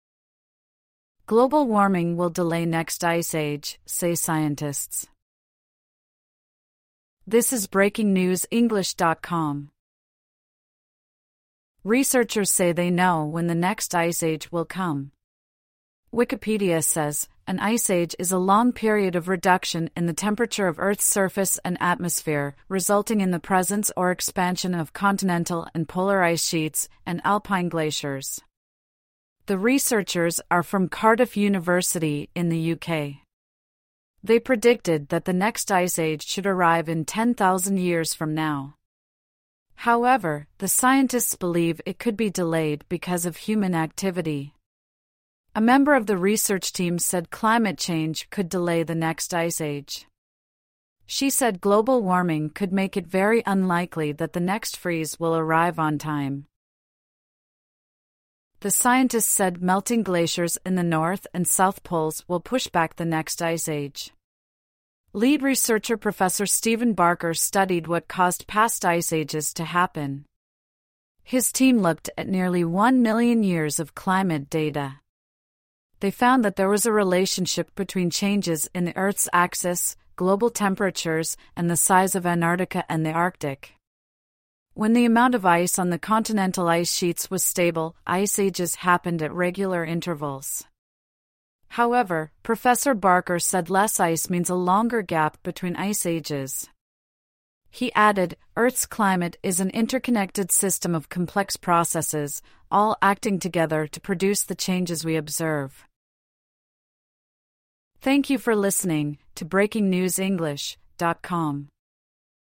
AUDIO (Normal)